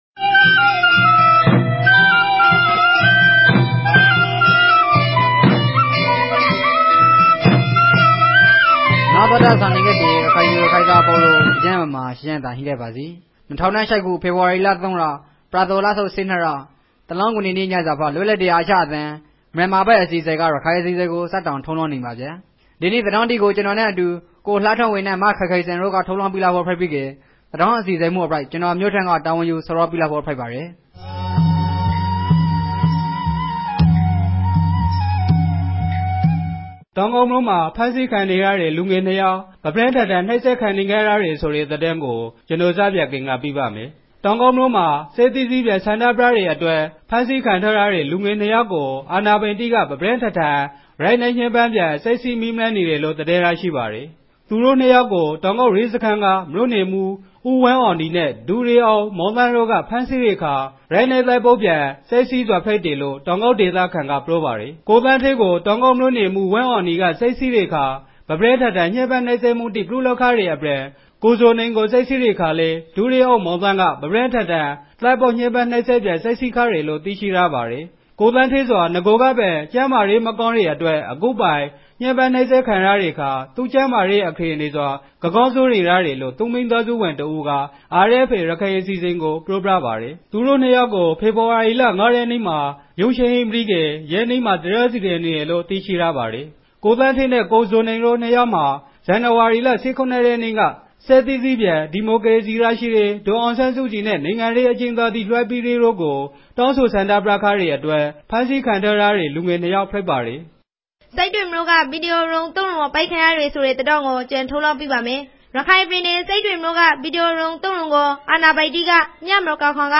ရခိုင်ဘာသာအသံလြင့်အစီအစဉ်မဵား